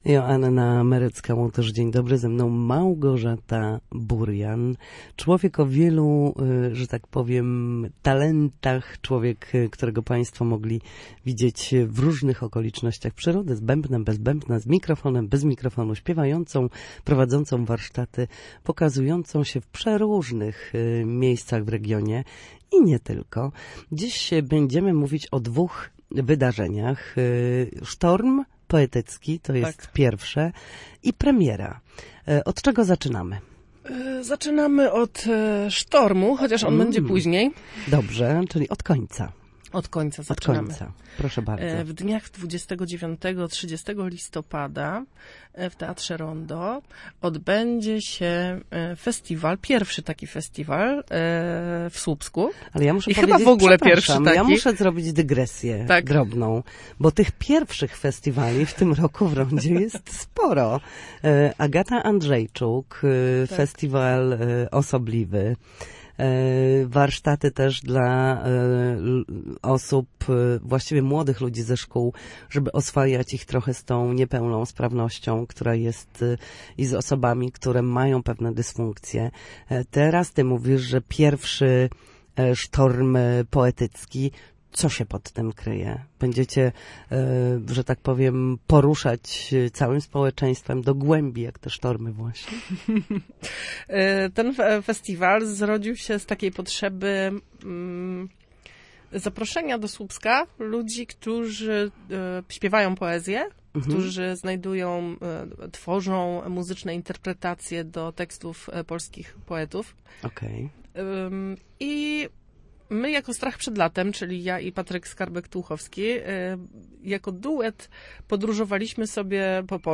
Na naszej antenie mówiła o zbliżającym się festiwalu poezji śpiewanej „Poetycki sztorm” oraz premiery wyśpiewanego monodramu.